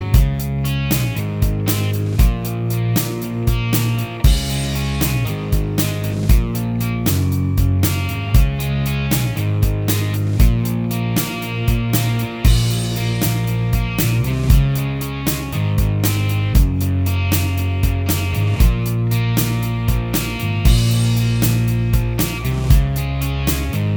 Minus Lead Guitar Rock 5:09 Buy £1.50